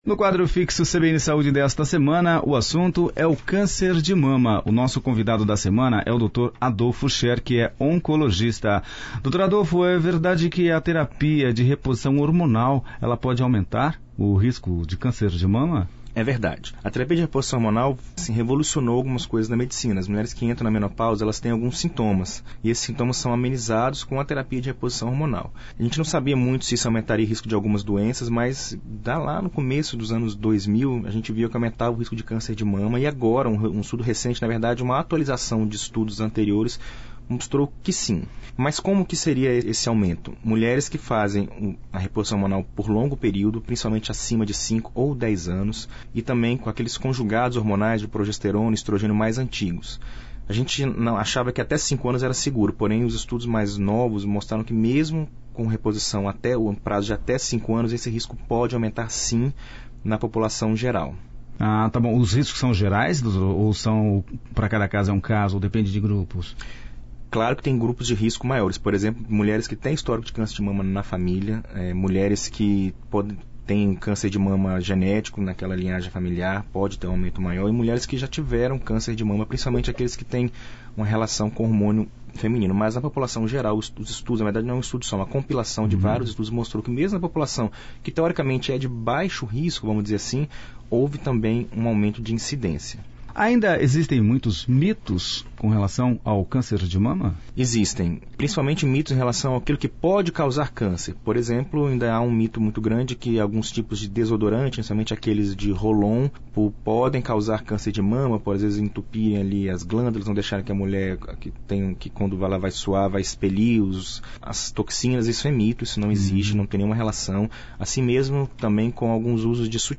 A entrevista foi ao ar em 22/10/2019